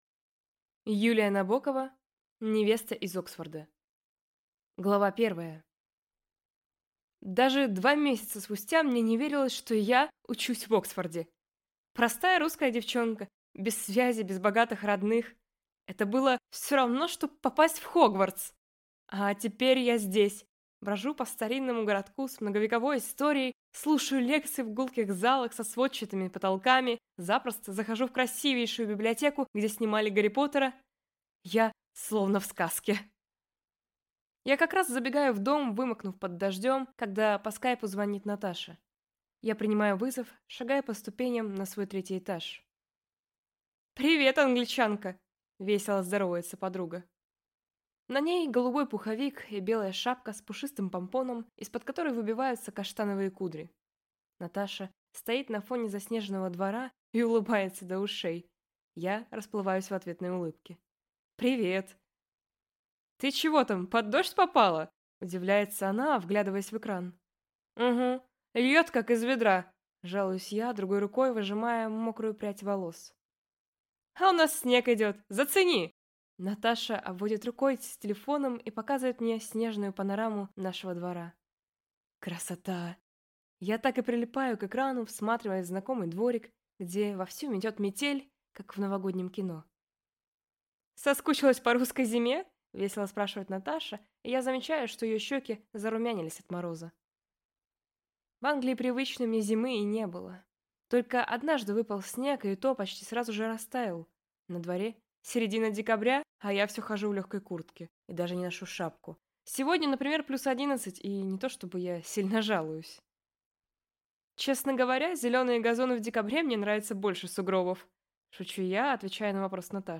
Аудиокнига Невеста из Оксфорда | Библиотека аудиокниг